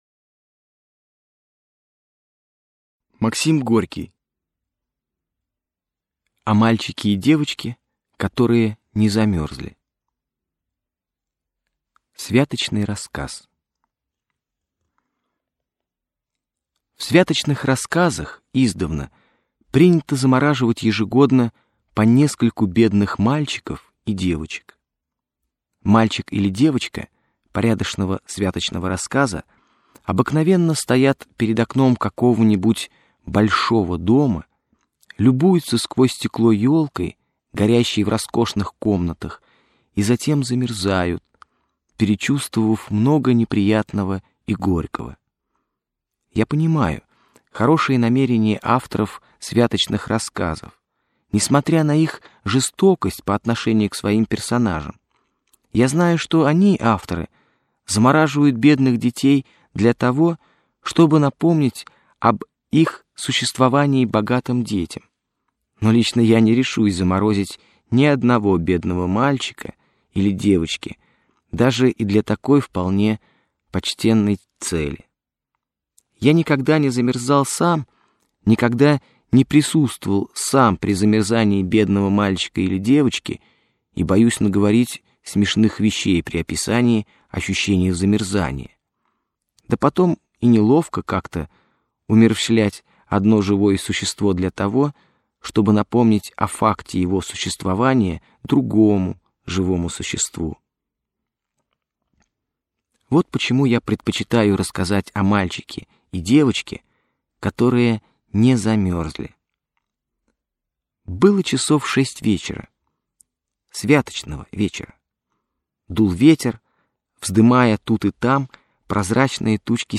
Аудиокнига Человек. Дед Архип и Ленька. О мальчике и девочке. которые не замерзли | Библиотека аудиокниг